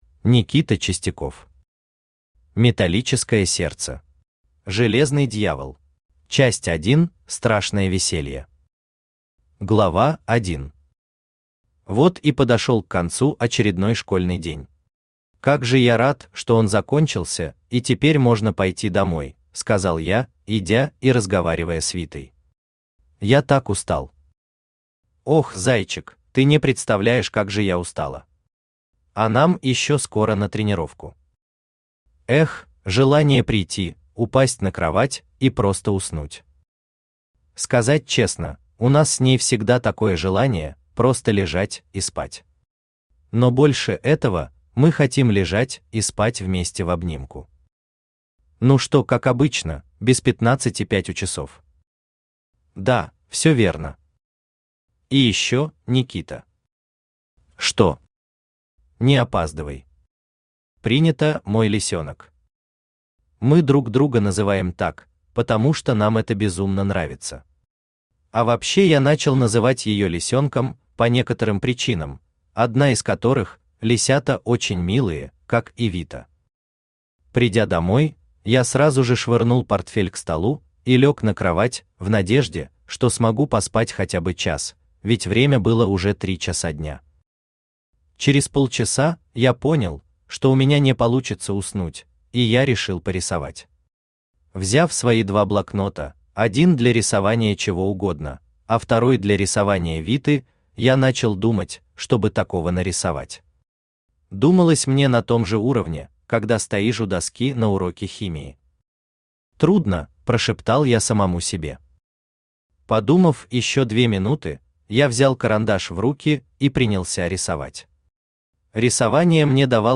Аудиокнига Металлическое сердце. Железный дьявол | Библиотека аудиокниг
Железный дьявол Автор Никита Евгеньевич Чистяков Читает аудиокнигу Авточтец ЛитРес.